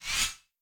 whooshForth_Far.wav